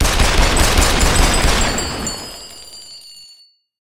shells.ogg